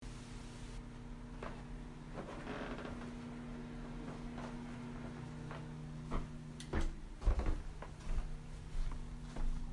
吱吱作响的脚步声
描述：走在吱吱作响的地板上
标签： 脚步 吱吱 吱吱 吱吱 吱吱响 地板 嘎嘎作响 摇摇欲坠的 走路
声道立体声